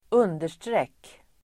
Ladda ner uttalet
understreck.mp3